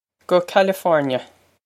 Pronunciation for how to say
Guh California.
This is an approximate phonetic pronunciation of the phrase.